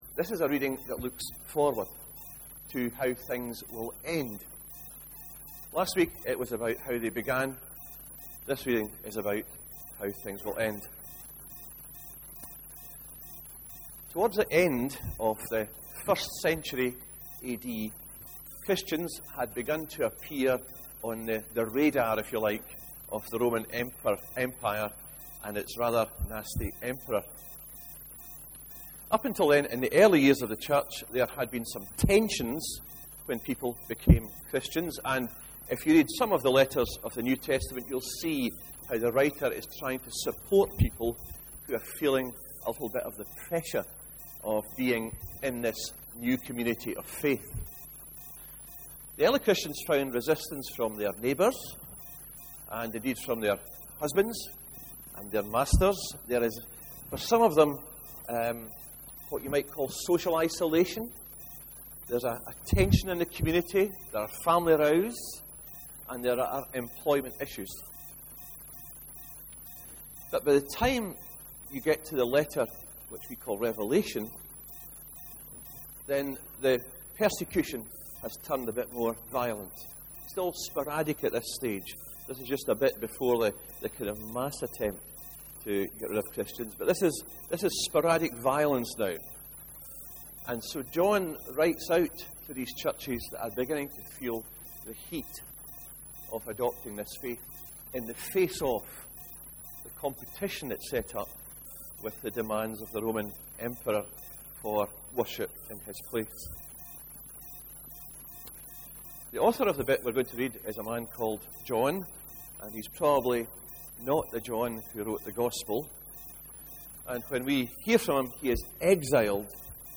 28/04/13 sermon – Looking at renewal rather than restoration (Revelation 21:1-6)